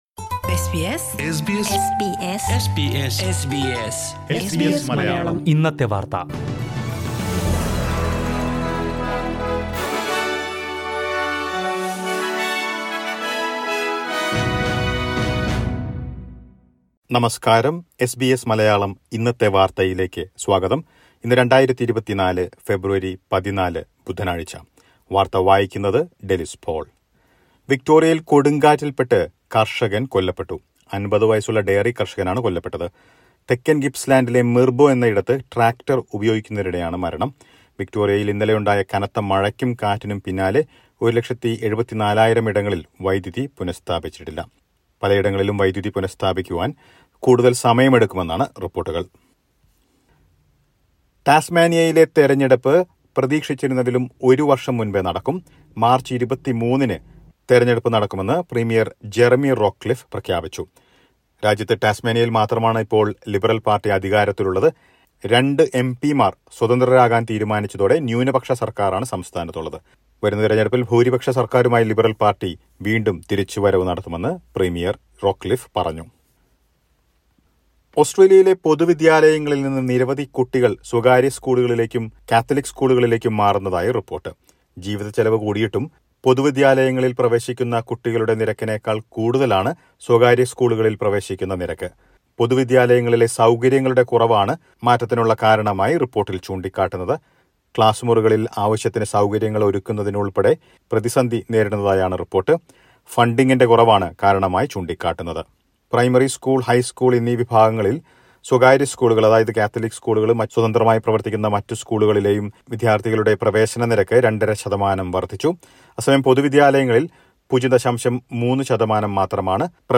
2024 ഫെബ്രുവരി 14ലെ ഓസ്ട്രേലിയയിലെ ഏറ്റവും പ്രധാന വാർത്തകൾ കേൾക്കാം...